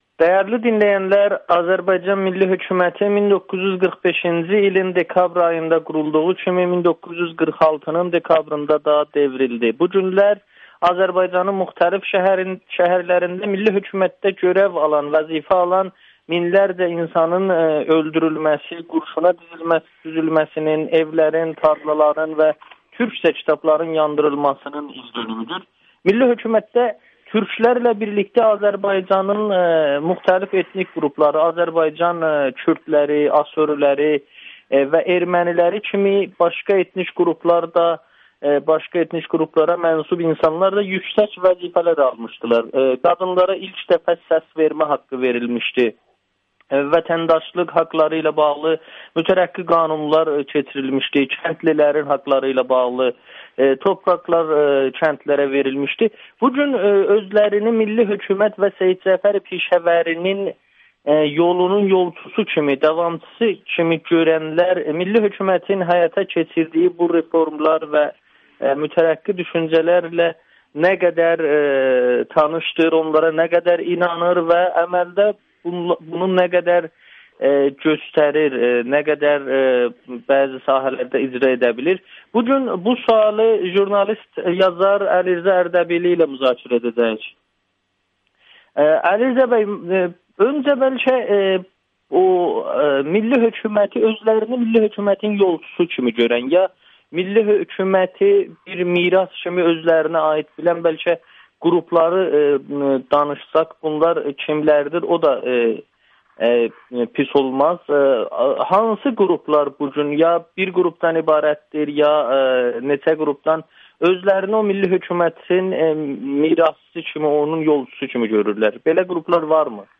Milli hərəkat milli hökümətin ideyalarını qəbul edirmi? [Audio-Müsahibə]